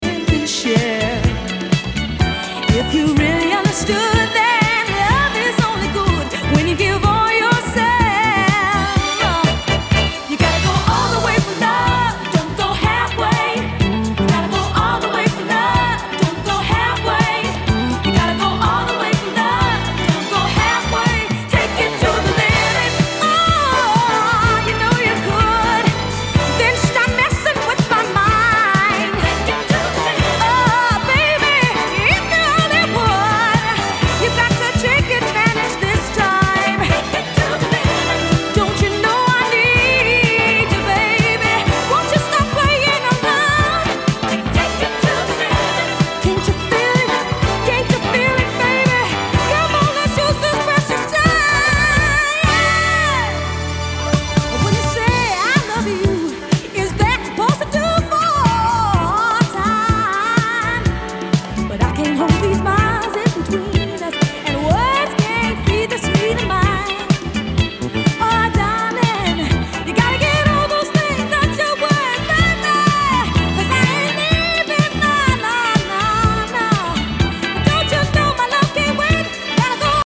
classic Philly Disco Soul joint
extending it in a chaotic peak time disco jam